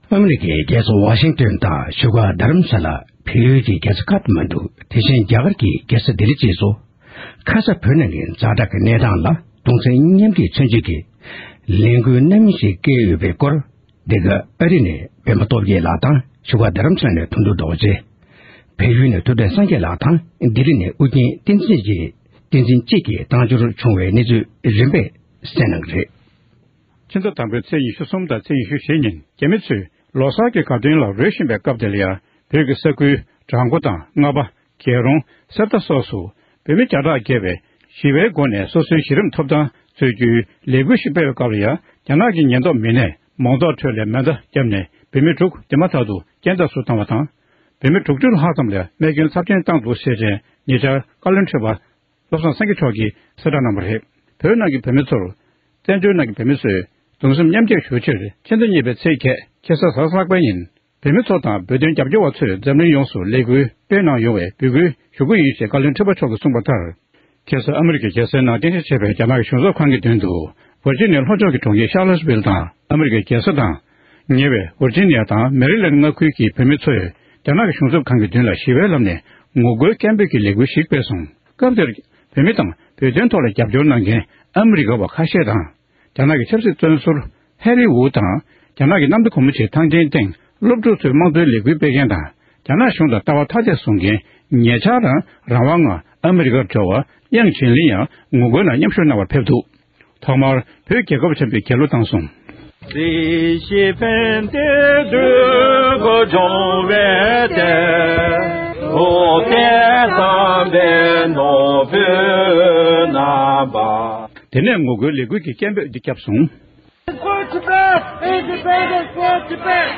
༄༅༎ཕྱི་ཟླ་༢ཚེས་༨རེས་གཟའ་ལྷག་པ་སྟེ་ལྷག་དཀར་གྱི་ཉིན་མོར་བཙན་བྱོལ་བོད་མིའི་སྒྲིག་འཛུགས་ཀྱི་བཀའ་ཤག་ནས་རེ་སྐུལ་གནང་བ་བཞིན་བཞུགས་སྒར་གཙོས་འཛམ་གླིང་གི་ས་ཕྱོགས་གང་སར་བོད་མི་དང་བོད་དོན་རྒྱབ་སྐྱོར་བས་བོད་ནང་བོད་དོན་ཆེད་འདས་གྲོངས་སུ་གྱུར་བ་རྣམས་དང༌།ད་ལྟ་རྒྱ་གཞུང་གི་དྲག་གནོན་གྱི་སྲིད་ཇུས་འོག་སྡུག་སྦྱོང་མྱོང་བཞིན་པ་རྣམས་ལ་གདུང་སེམས་མཉམ་བསྐྱེད་མཚོན་ཆེད་དུ་ཉིན་རྒྱ་དུས་གཅིག་དུ་མཆོད་འབུལ་སྨོན་ལམ་འདོན་པ་དང༌།རྒྱ་ནག་གཞུང་ལ་ངོ་རྒོལ་གྱི་ལས་འགུལ་སྤེལ་ཡོད་པའི་སྐོར་ཨེ་ཤེ་ཡ་རང་དབང་རླུང་འཕྲིན་ཁང་གི་གསར་འགོད་ཁག་ཅིག་ནས་བཏང་བའི་གནས་ཚུལ་ལ་གསན་རོགས༎